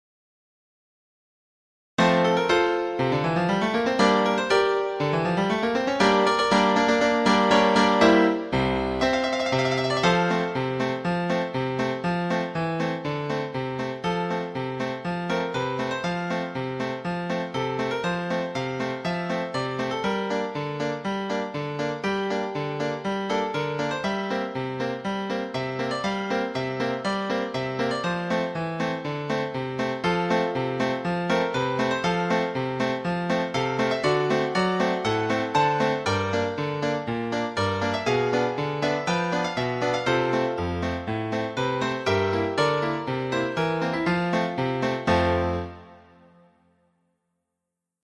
タイトル：行進曲「陽光」 （1986.3）
トリオ（行進曲の中間部）のメロディーには、当時の中学校の校歌を使っています。